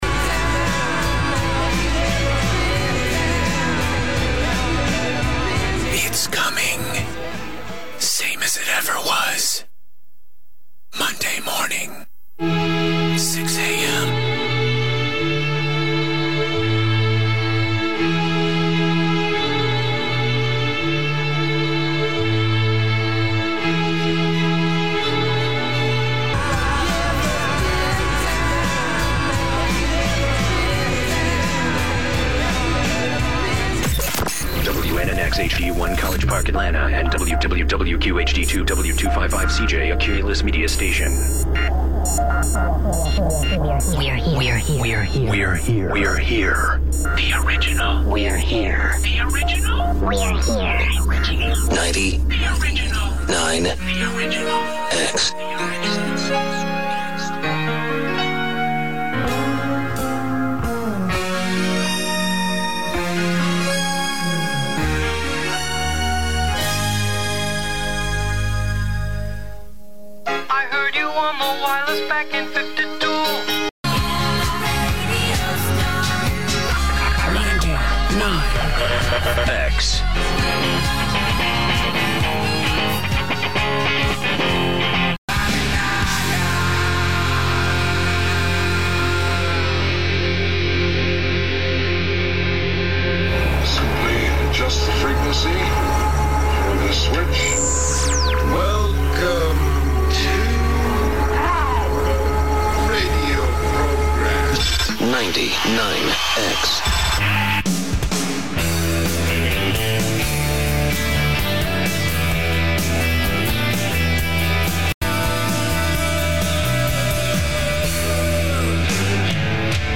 Previous Format: Rock “Rock 100.5
New Format: Classic Alternative “99X